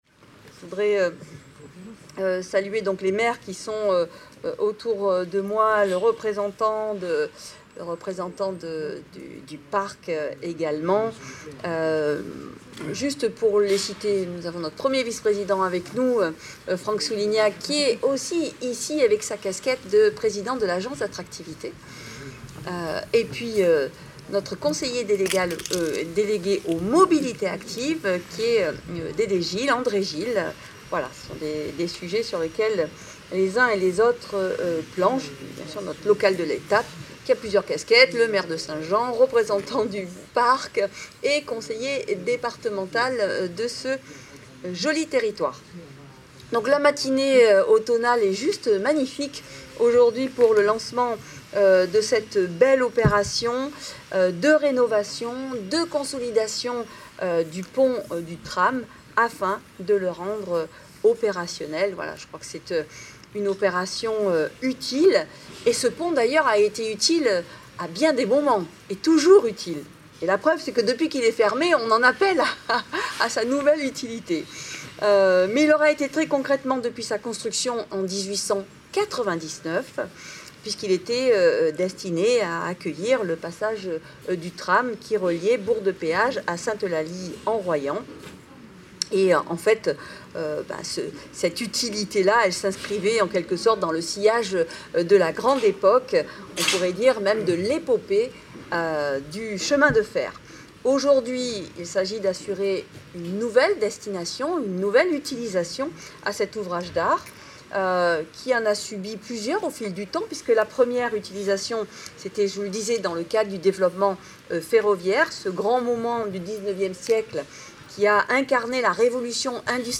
Discours de Marie-Pierre Mouton, présidente du département de la Drôme, Olivier Béraldin, maire de St Laurent en Royans, Christian Morin, maire de St Jean en Royans et Marie Guirimand, élue au sein de la communauté de communes Royans-Vercors